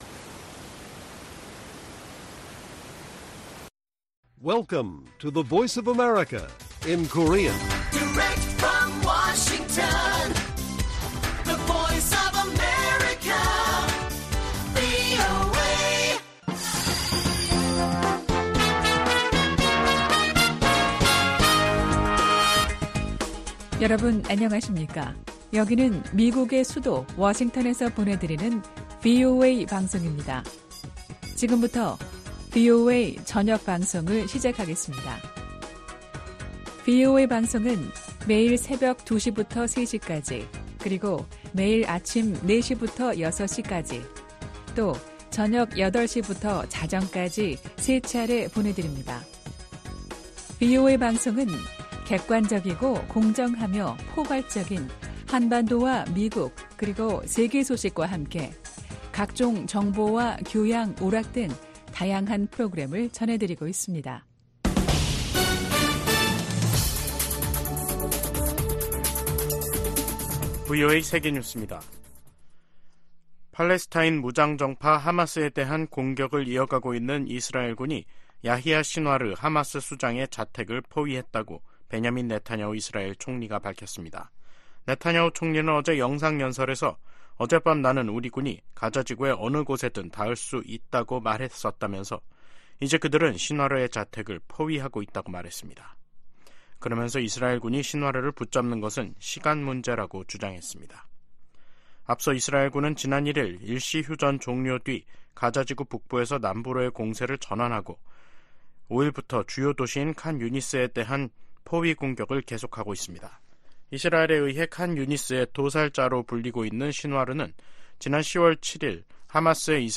VOA 한국어 간판 뉴스 프로그램 '뉴스 투데이', 2023년 12월 7일 1부 방송입니다.